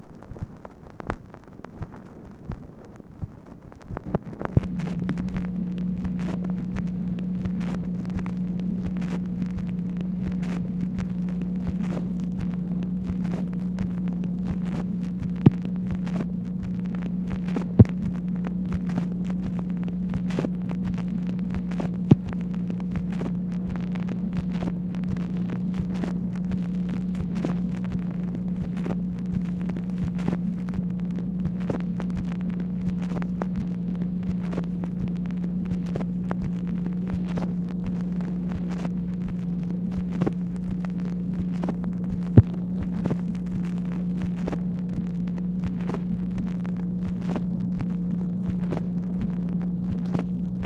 MACHINE NOISE, July 30, 1964
Secret White House Tapes